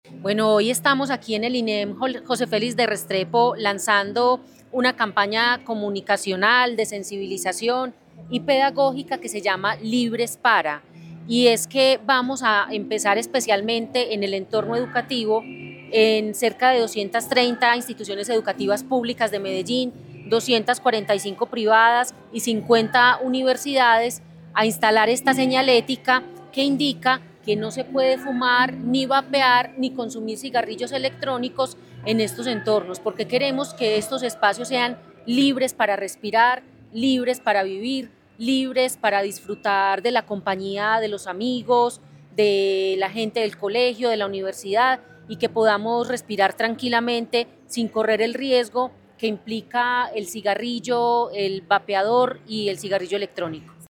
Declaraciones secretaria de Salud, Natalia López Delgado.
Declaraciones-secretaria-de-Salud-Natalia-Lopez-Delgado.-Humo-y-vapeo.mp3